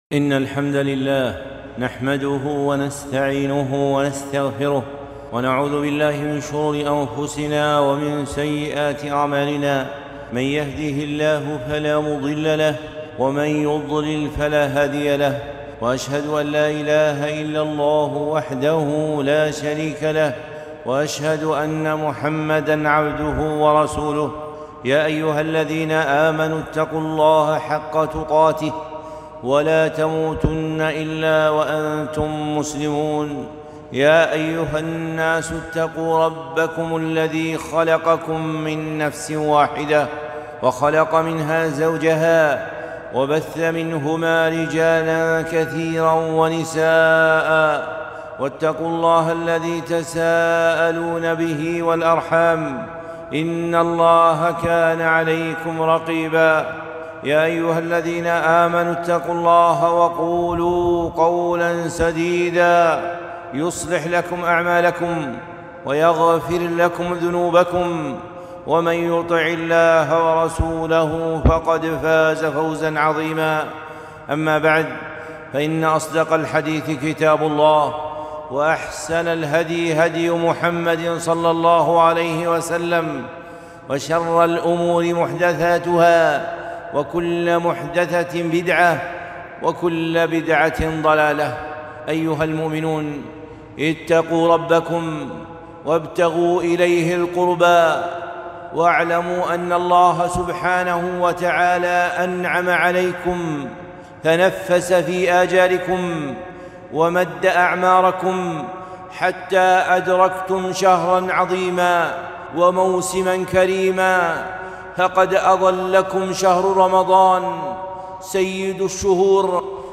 خطبة - تعظيم رمضان